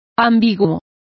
Complete with pronunciation of the translation of equivocal.